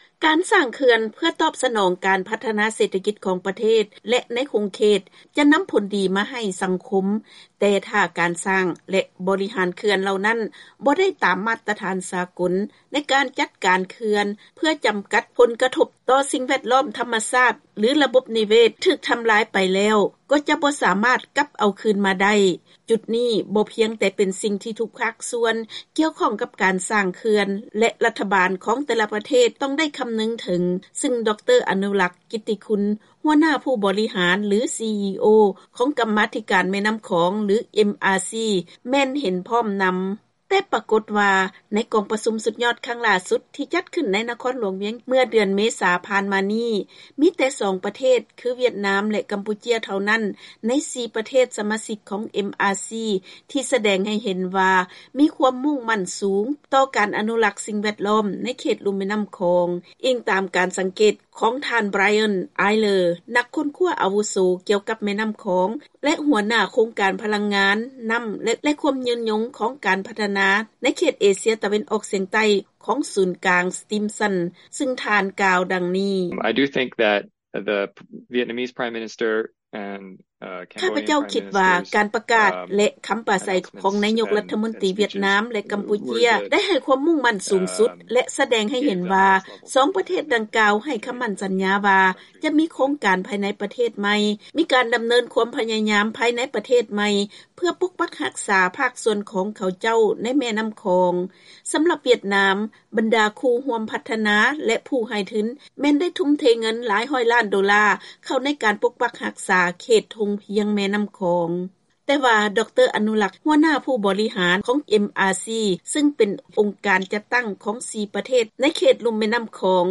ເຊີນຟັງລາຍງານກ່ຽວກັບການປະຕິບັດກົດລະບຽບດ້ານປ້ອງກັນຜົນກະທົບທີ່ເກີດມາຈາກເຂື່ອນຢູ່ໃນເຂດລຸ່ມແມ່ນນ້ຳຂອງ